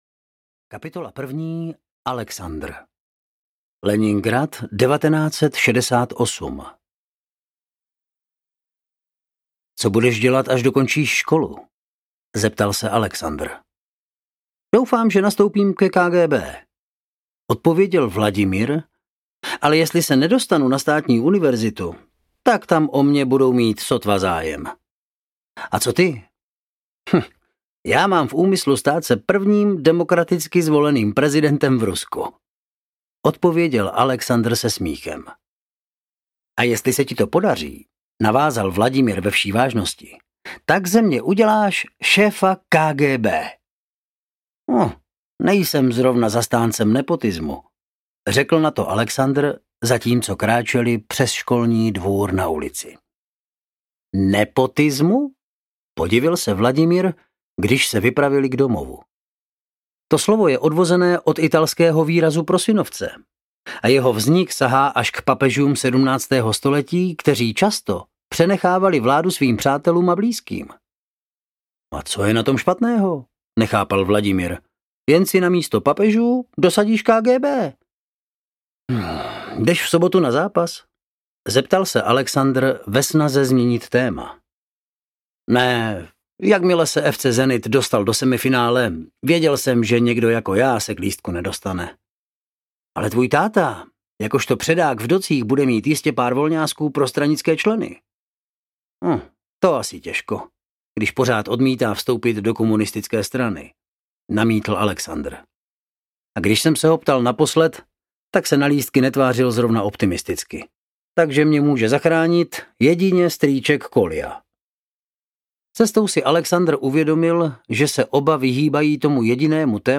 Panna vítězí audiokniha
Ukázka z knihy